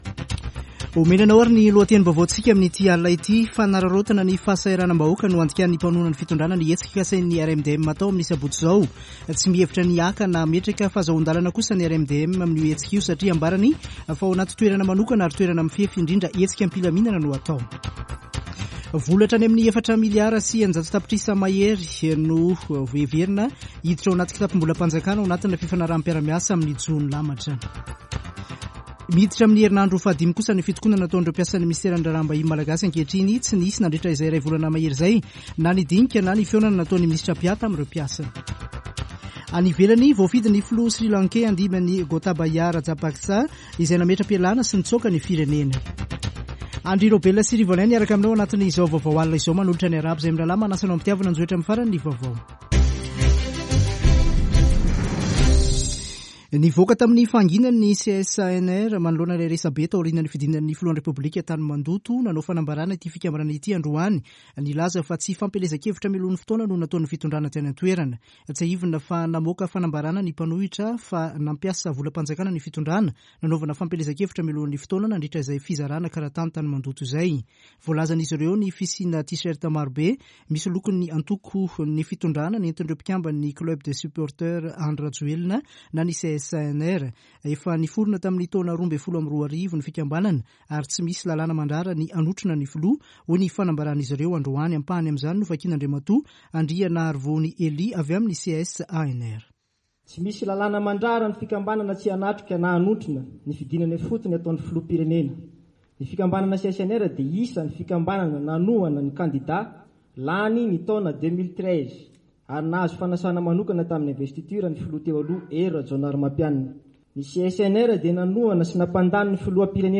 [Vaovao hariva] Alarobia 20 jolay 2022